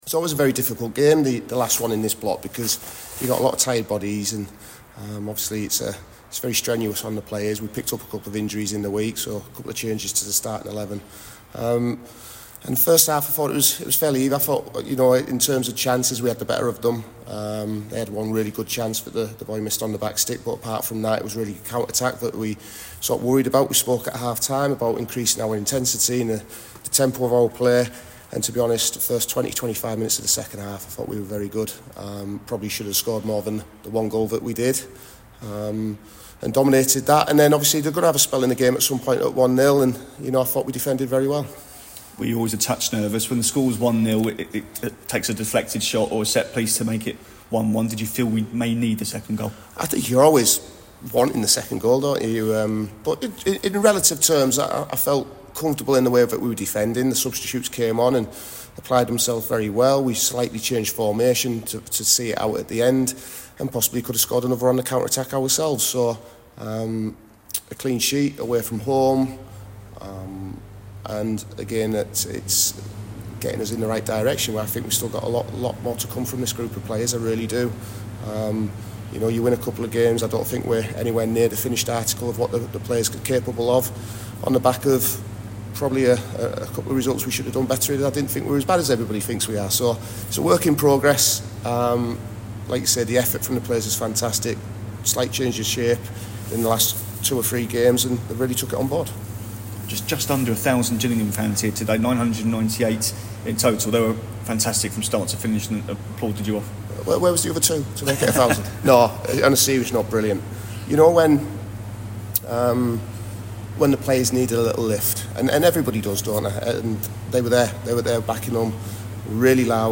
spoke to reporters after their victory over Colchester